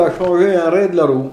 Mots Clé charrette(s), tombereau(x), ; Localisation Saint-Hilaire-des-Loges
Catégorie Locution